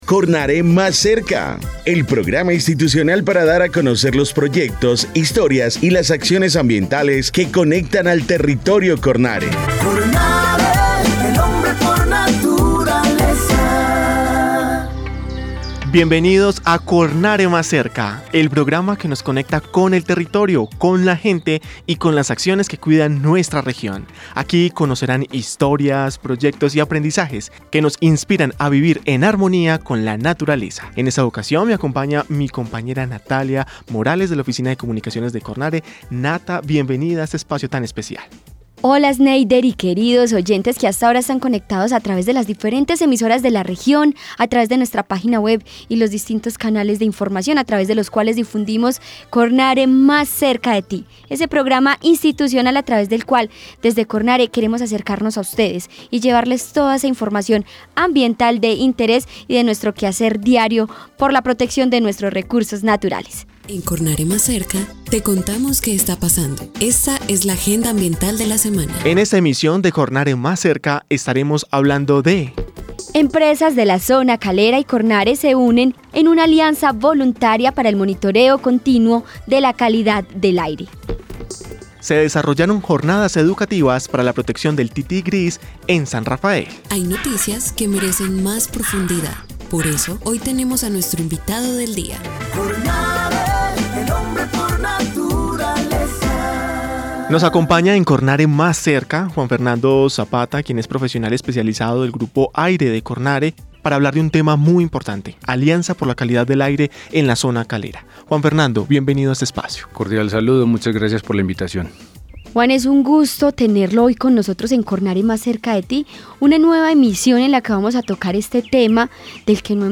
Programa de radio 2025